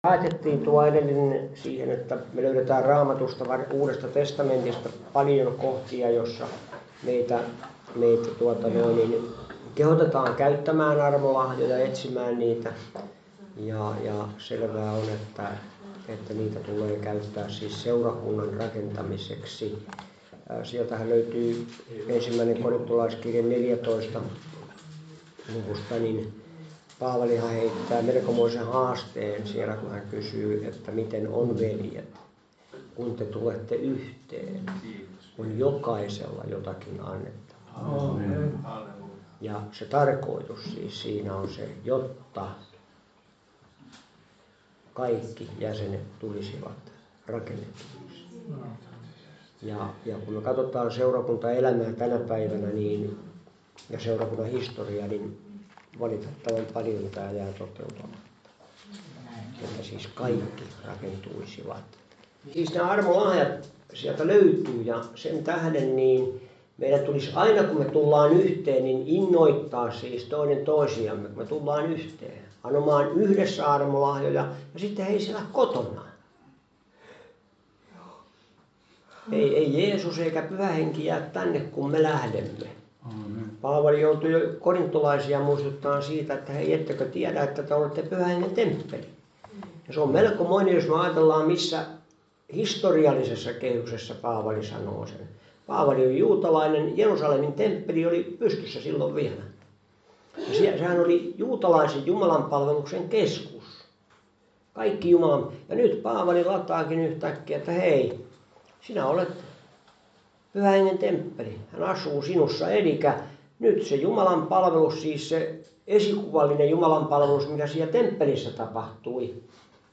Service Type: Raamattutunti